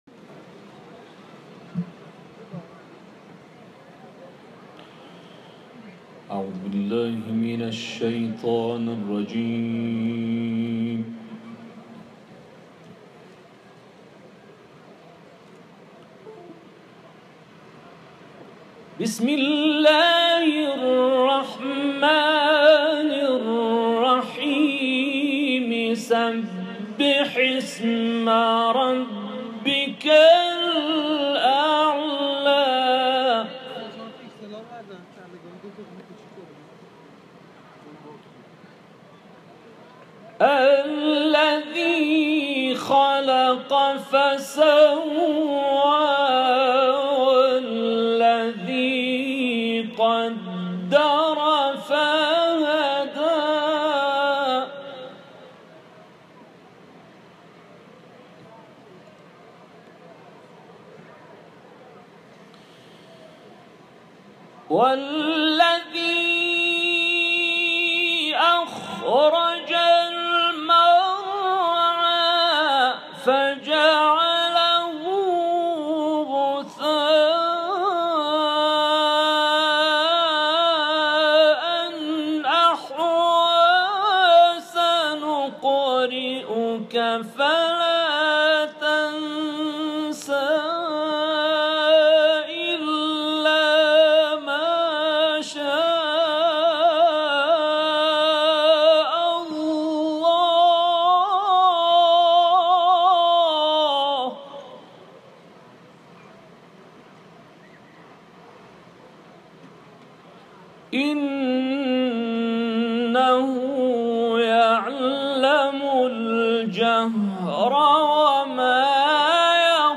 این تلاوت به تازگی در شهر تهران اجرا شده است.